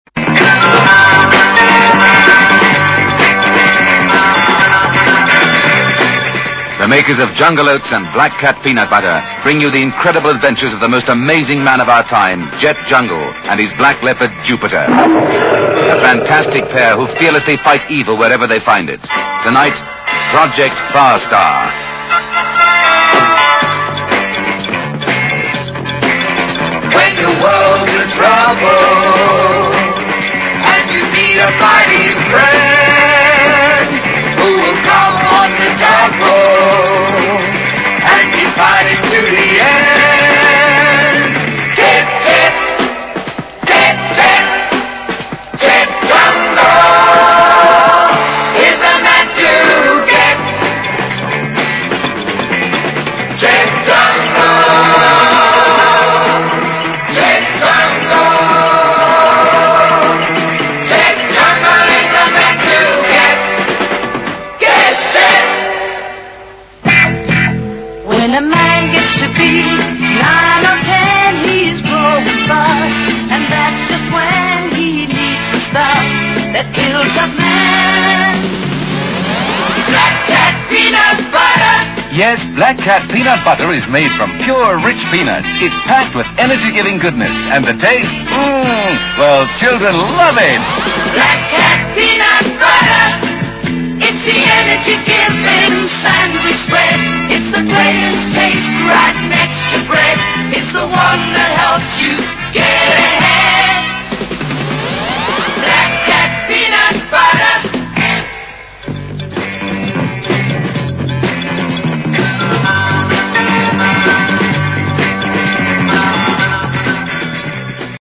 Jet Jungle Ad [Audio 🎵]